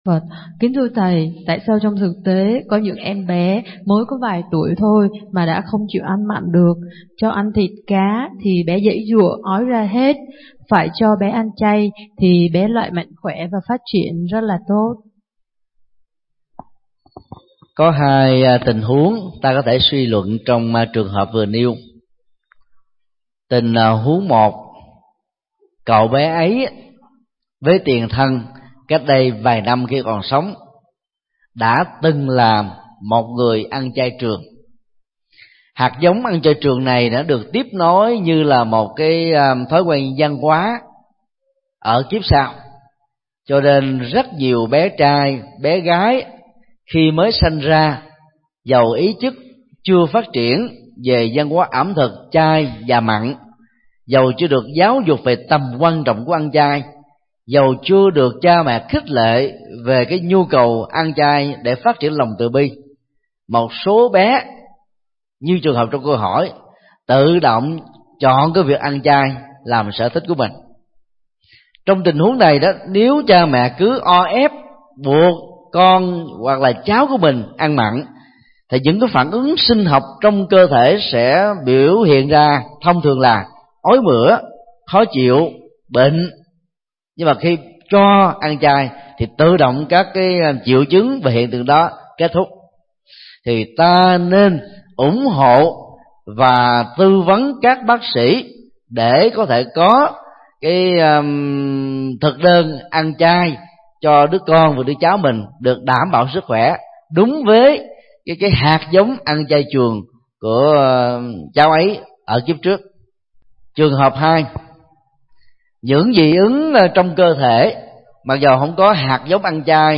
Vấn đáp: Hạt giống ăn chay trường của trẻ em – Thích Nhật Từ